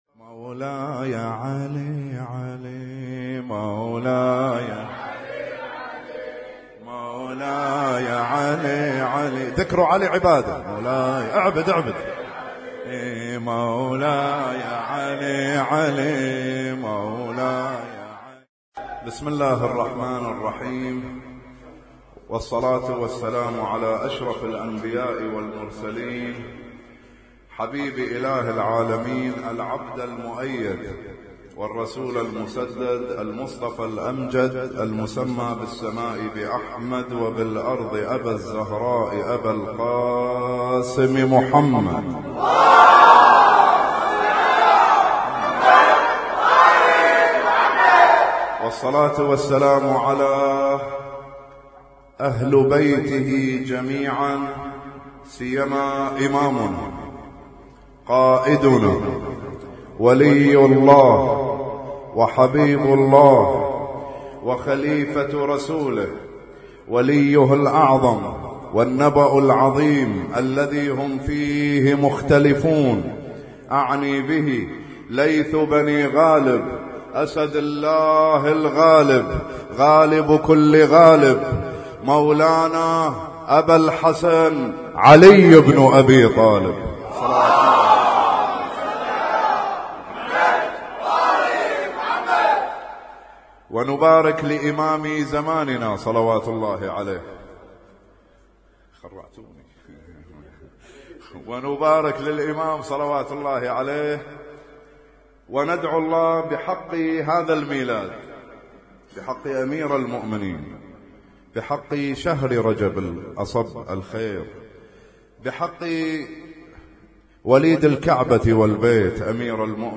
اسم التصنيف: المـكتبة الصــوتيه >> المواليد >> المواليد 1436